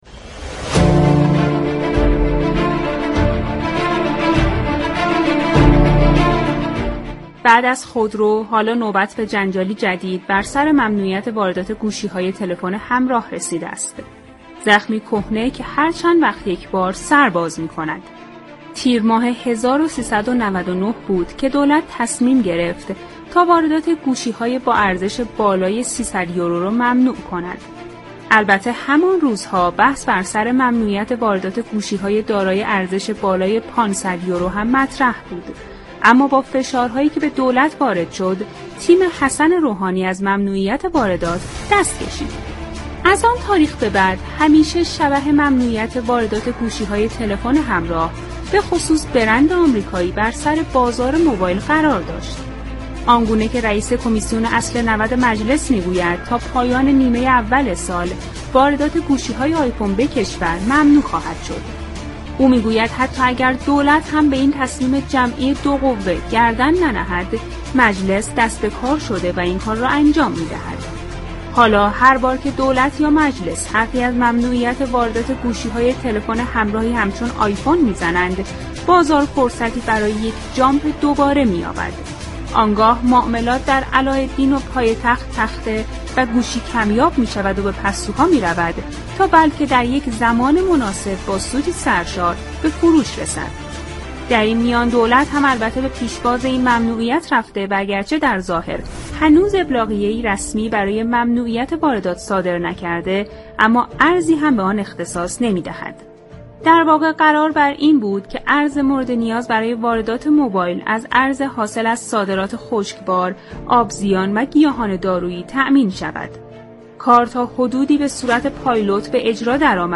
جعفر قادری عضو كمیسیون اقتصادی مجلس شورای اسلامی درخصوص ممنوعیت واردات گوشی‌های لوكس و بالای 600 دلار با برنامه بازار تهران رادیو تهران به گفتگو پرداختند.